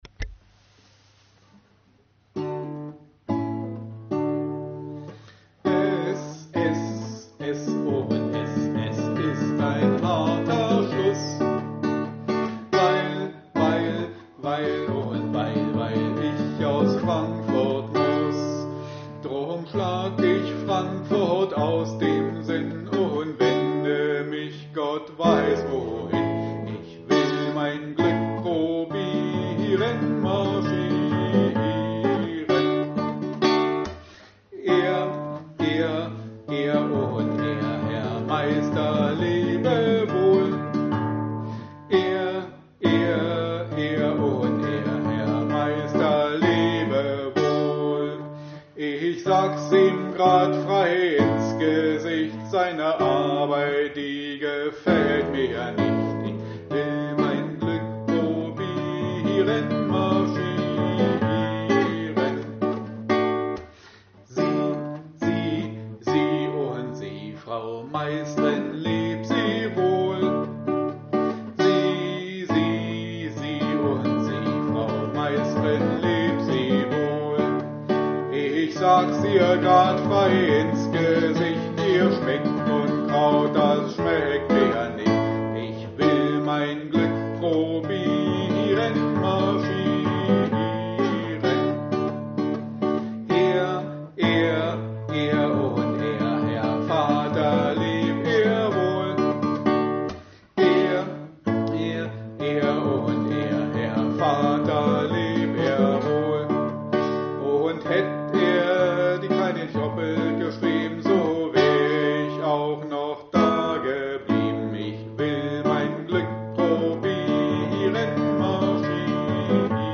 Da auch wir zur Zeit in vielen Seniorenheimen nicht arbeiten können und folglich auch keine Gruppen stattfinden, haben wir Ihnen ein kleines Liederbuch mit dazugehöriger Begleitung zusammengestellt.